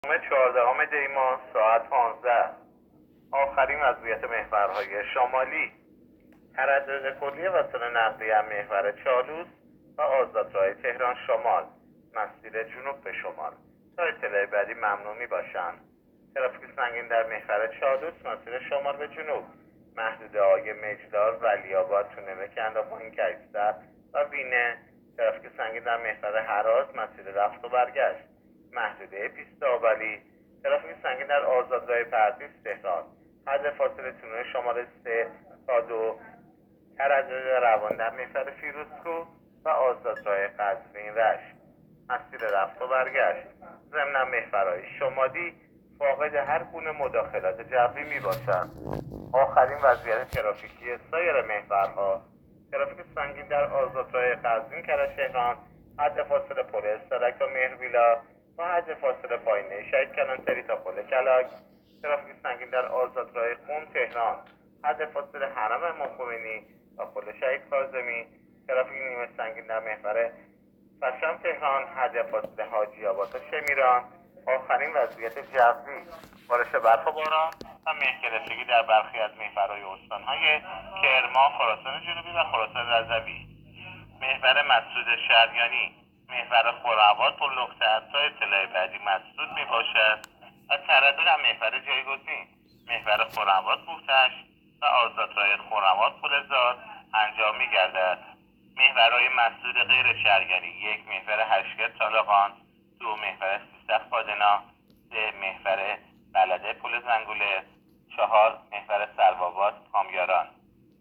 گزارش رادیو اینترنتی از آخرین وضعیت ترافیکی جاده‌ها تا ساعت ۱۵ چهاردهم دی؛